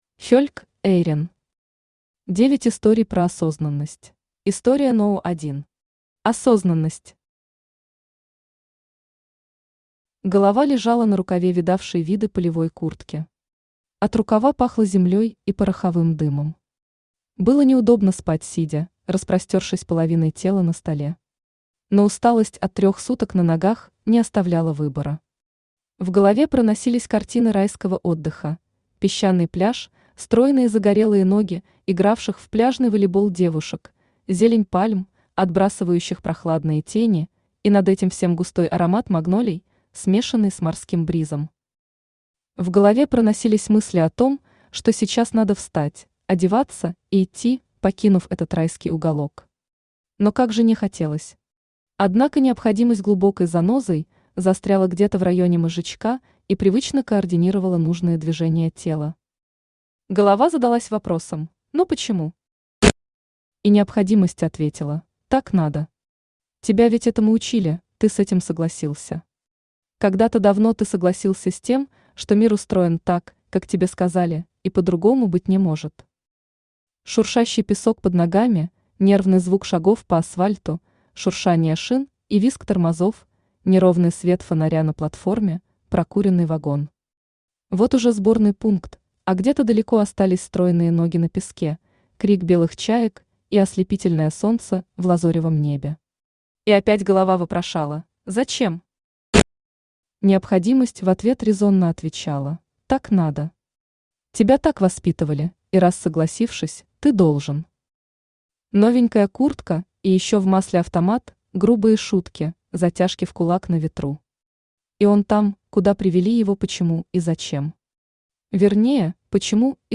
Аудиокнига 9 историй про осознанность | Библиотека аудиокниг